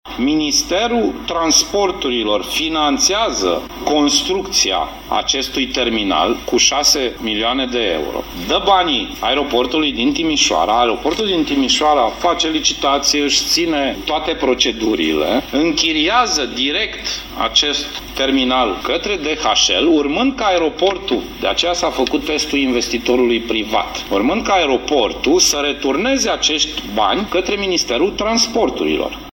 Suma va trebui, însă, returnată, spune ministrul Trasporturilor, Sorin Grindeanu.
Banii vor trebui returnați Ministerului Transporturilor în maximum șapte ani, a mai spus ministrul Sorin Grindeanu.